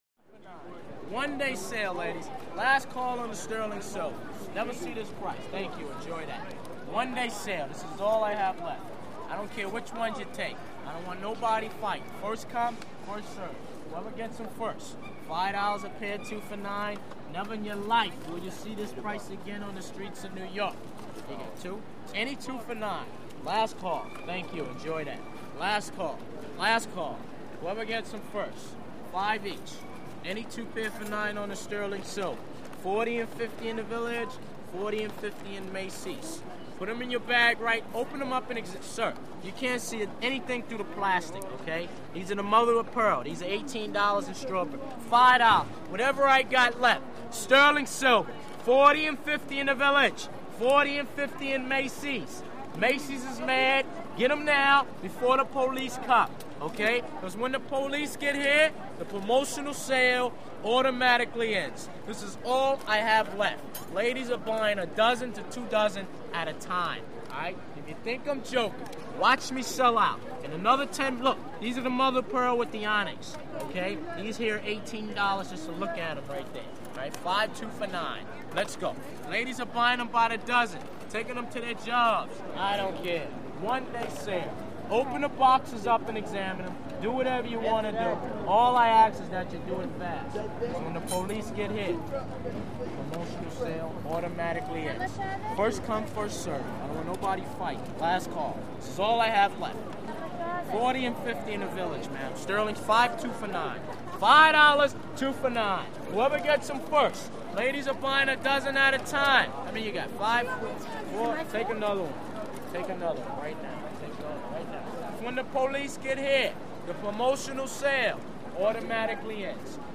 New York Street Vendor Sells Cheap Jewelry, Close Perspective. Humorous Patter. Medium Pedestrian Walla, A Few Women Ask, How Much Are They? .